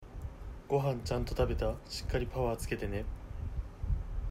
選手ボイス